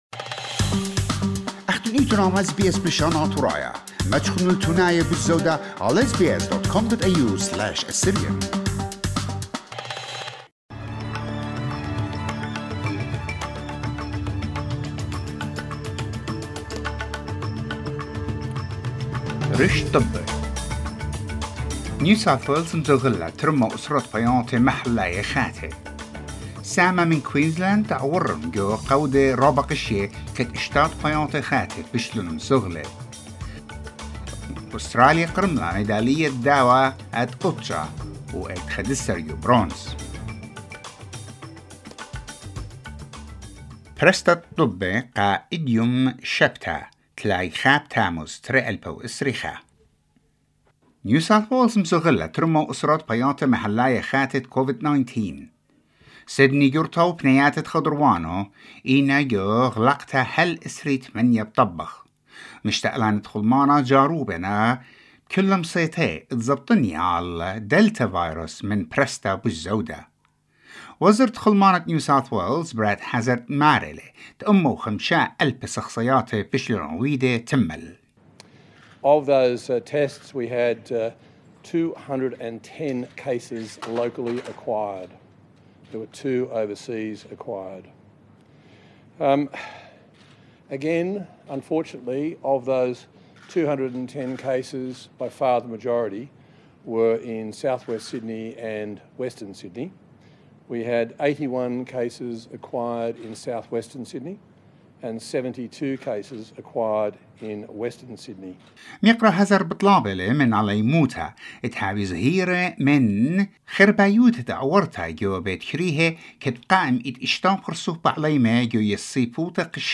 SBS NEWS IN ASSYRIAN 31 JULY 2021